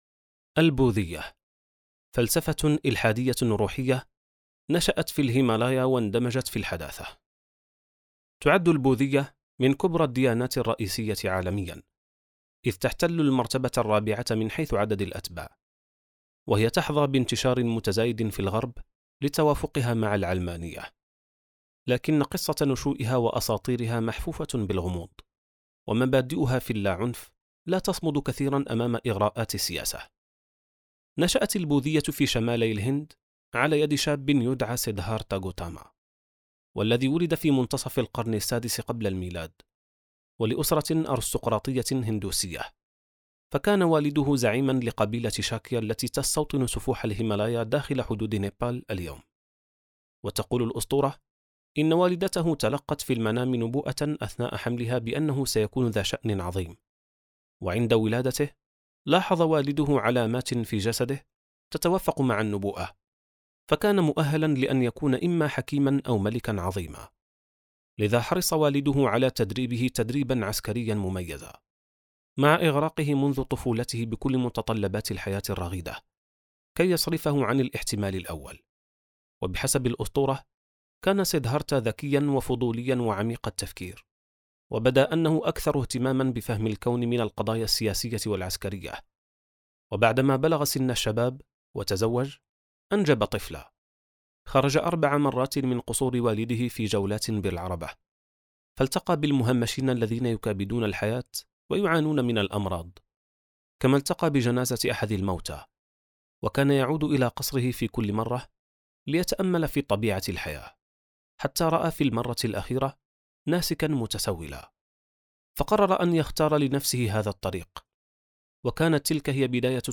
كتاب صوتي | خارطة الطوائف (719): البوذية • السبيل